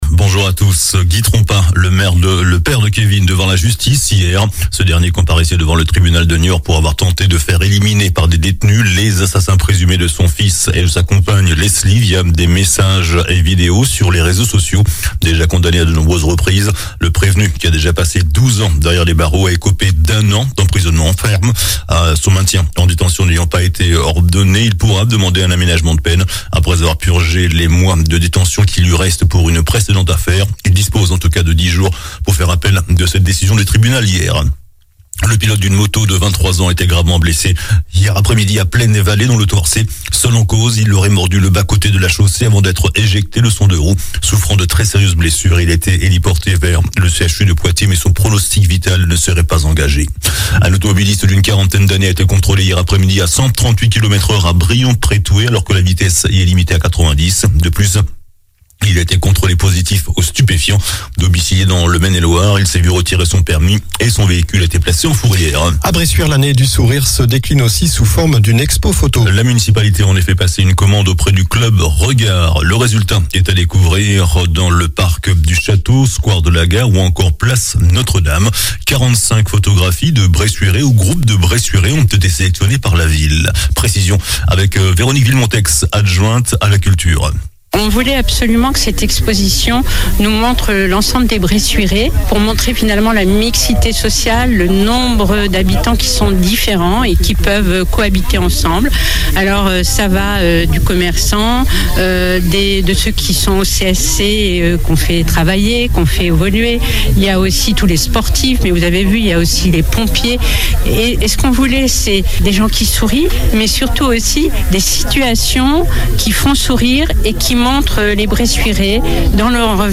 Journal du samedi matin 3 juin